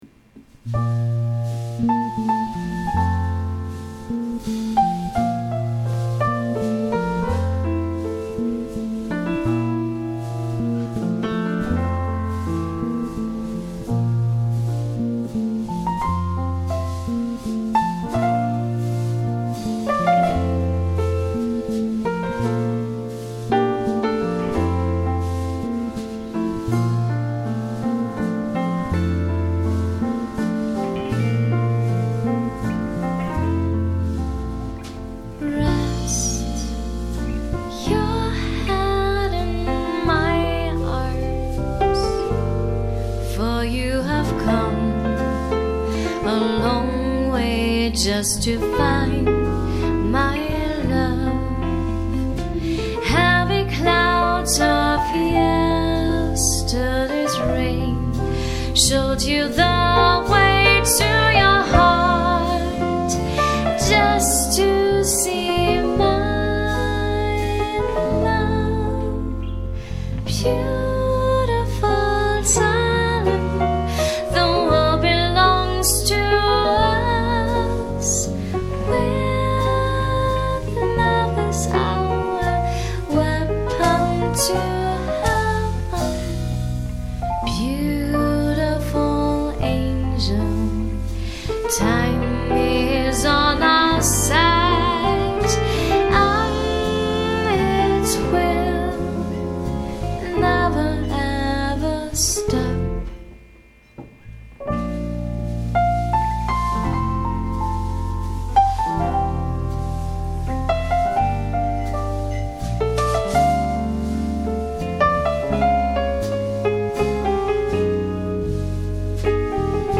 Ballade
Big Band